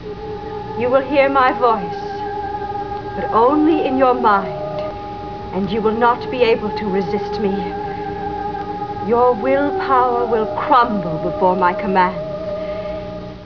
[6] Lara & Angelique Speak!  ( Wave files )